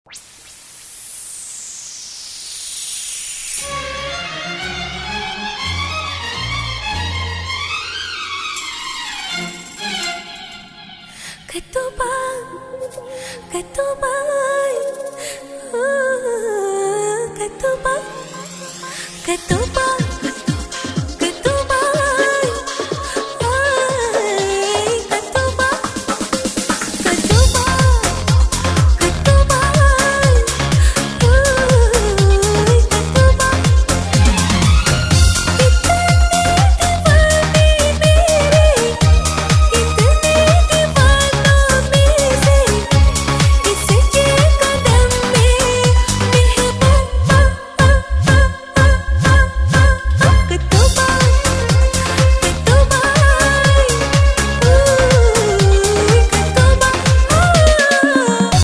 • Качество: 128, Stereo
поп
женский вокал
зажигательные
индийские мотивы